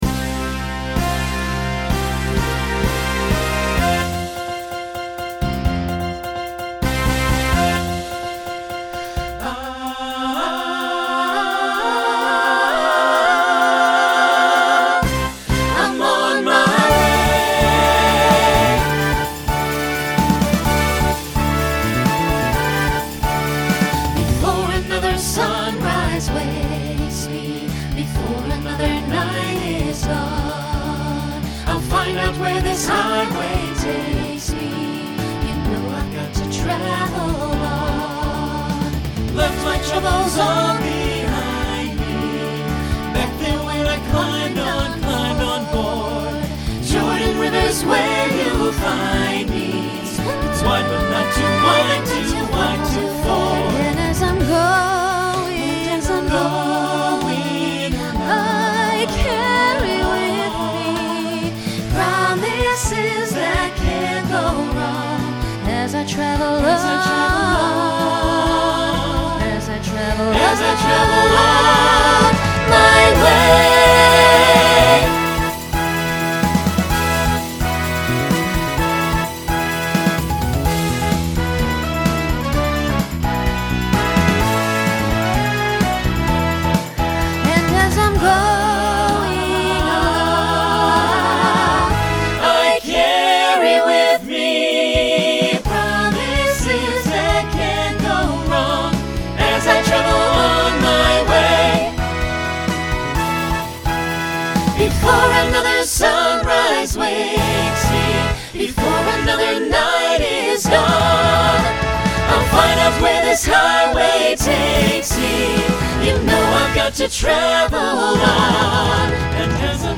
Genre Broadway/Film
Show Function Opener Voicing SATB